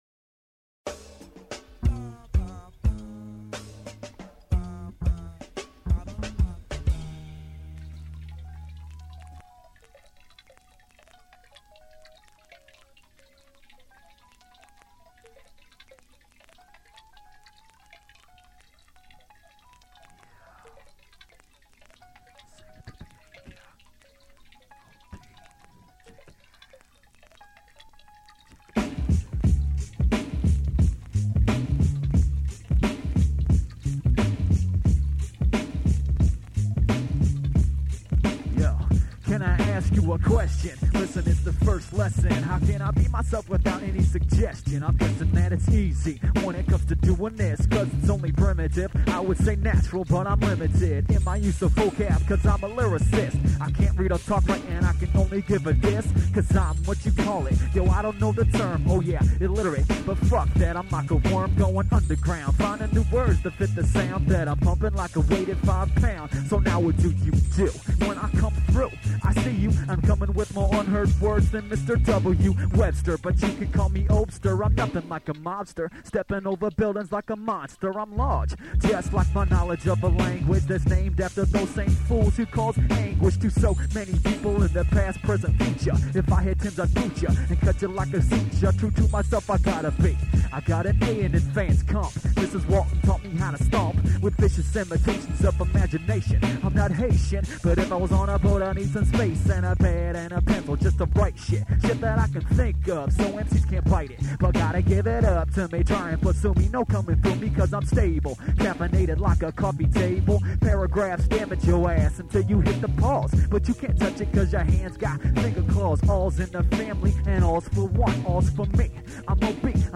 Compact and colossal.